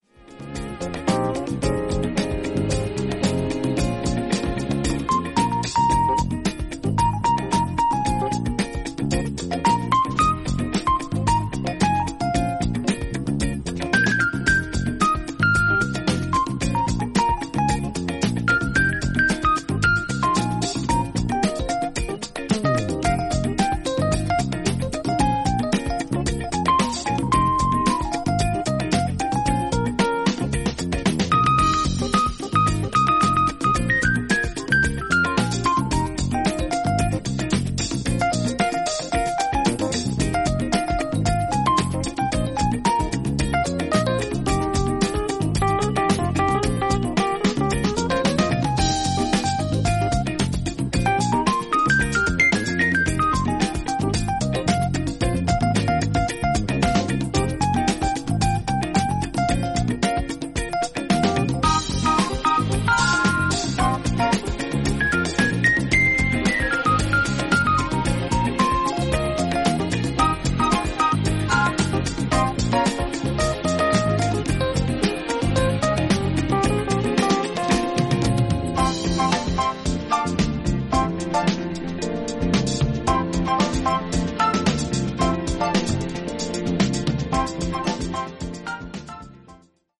程よくグルーヴィーで清涼感のあるライト・フュージョン